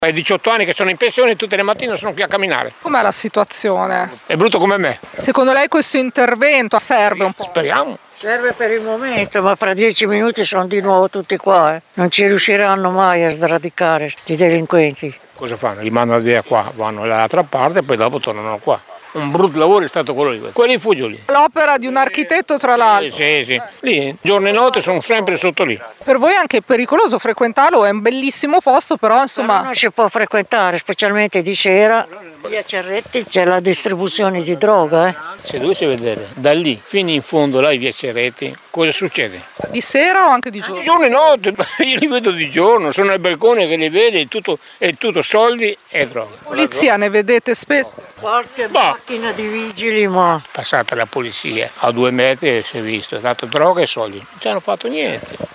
residenti e frequentatori del parco
vox-parco-.mp3